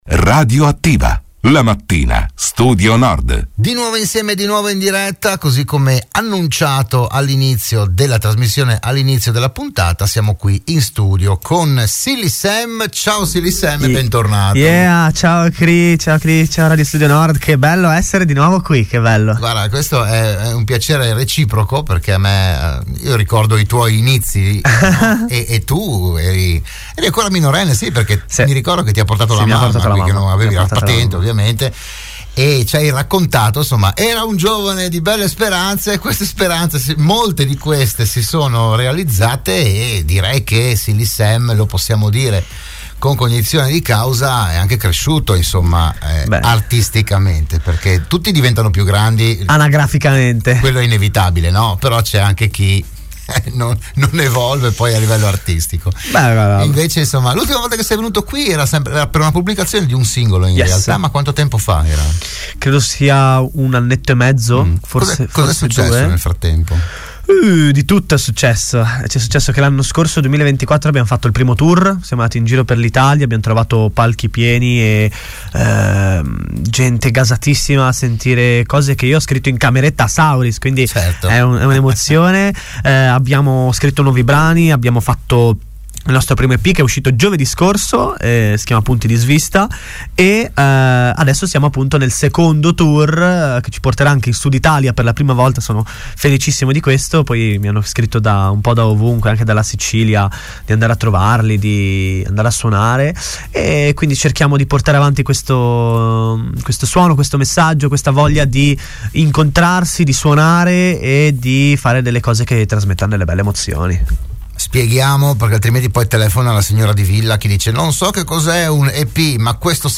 Oggi la presentazione a "RadioAttiva" di Radio Studio Nord